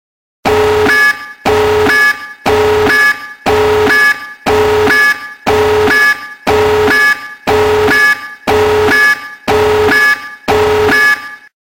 Toque para Celular Alarme Nuclear
Com o som de uma sirene poderosa, ele é ideal para quem gosta de toques marcantes e diferentes.
toque-para-celular-alarme-nuclear-pt-www_tiengdong_com.mp3